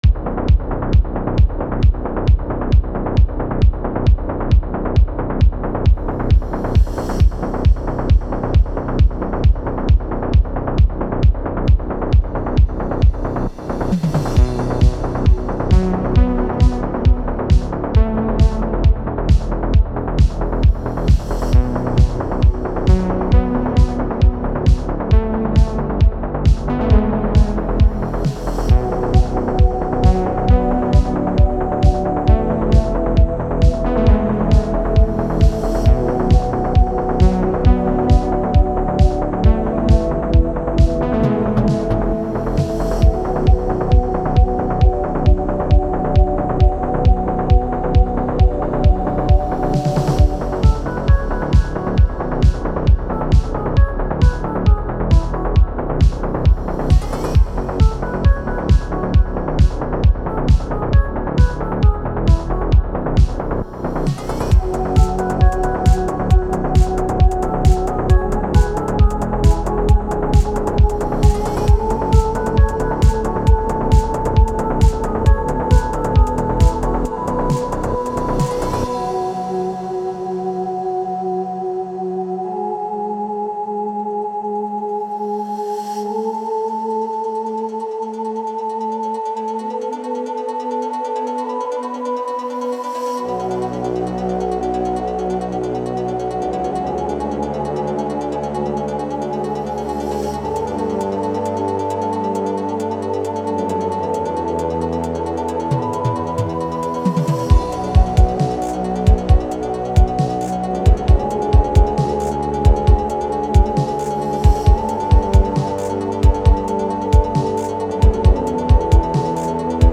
Retrowave